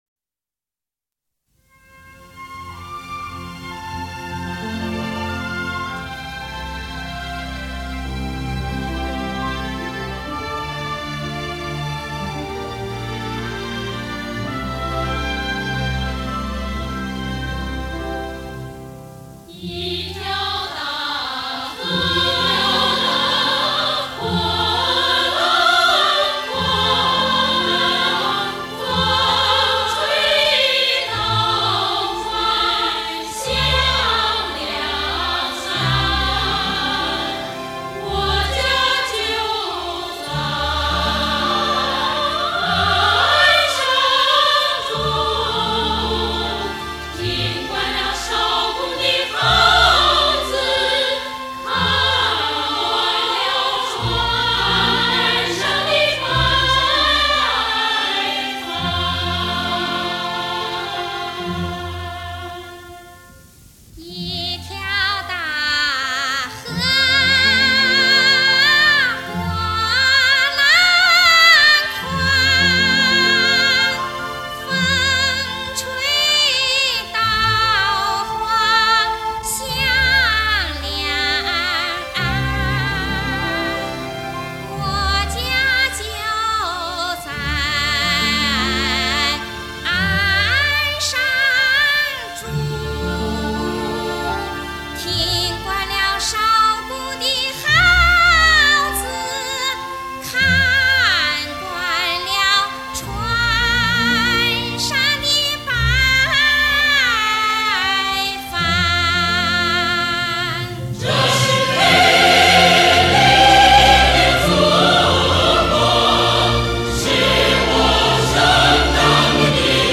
音质：正版CD转320K/MP3